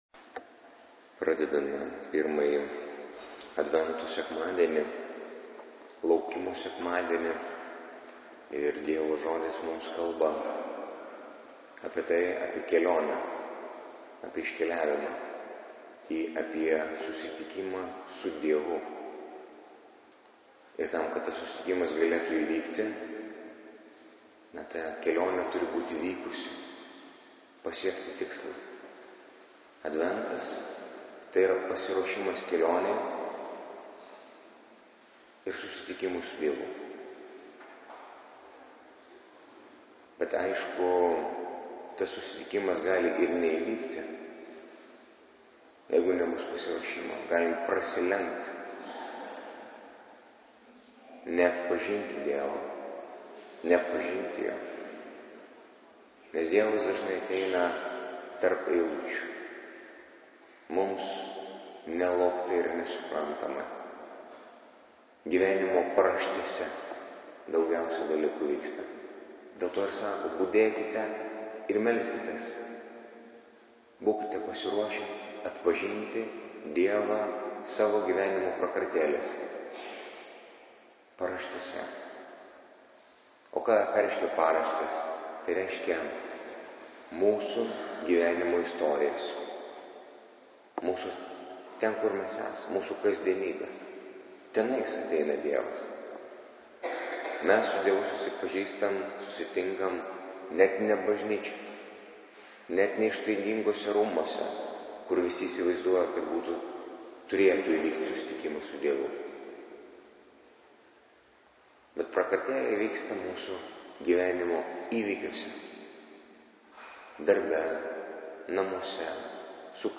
Audio pamokslas Nr1: 2016-11-27-i-advento-sekmadienis